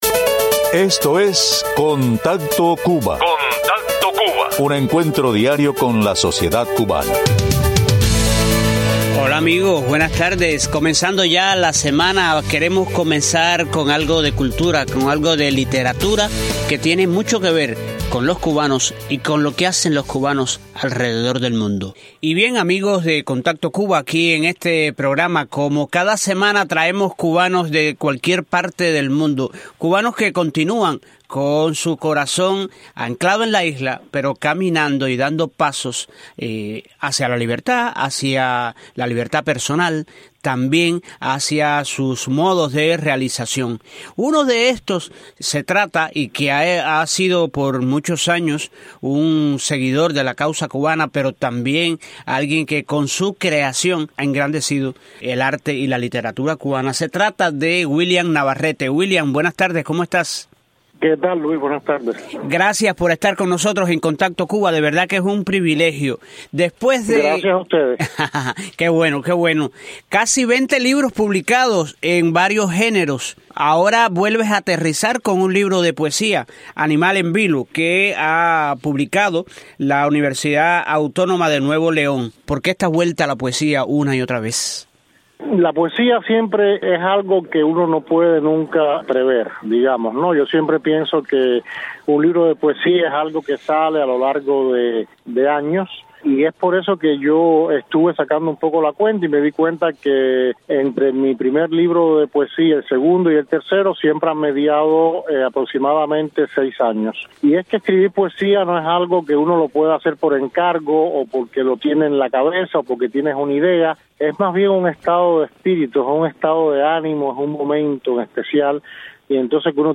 A petición de los oyentes de Contacto Cuba, entrevistamos a cubanos que desarrollan su labor fuera de Cuba.